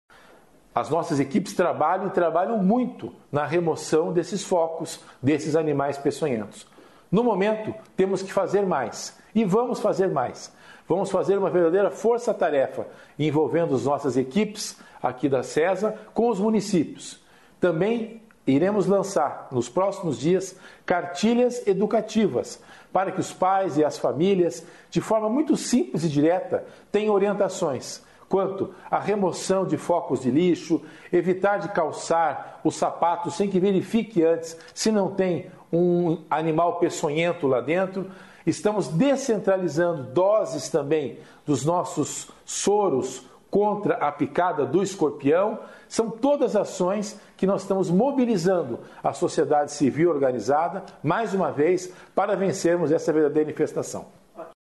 Sonora do secretário da Saúde em exercício, César Neves, sobre a força-tarefa contra os escorpiões